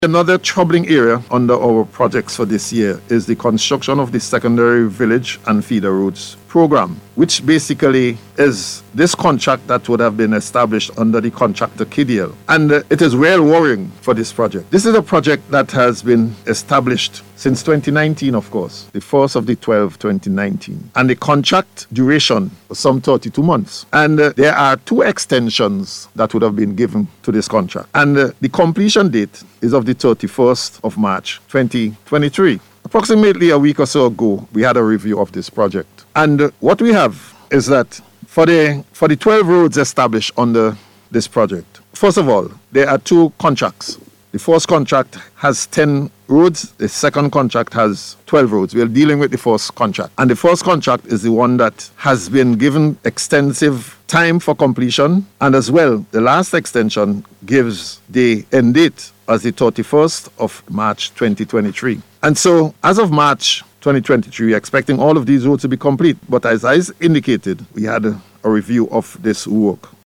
Speaking on NBC Radio’s Face to Face Programme on Wednesday, Minister Daniel pointed out that the project which commenced in December of 2019 had some major setbacks.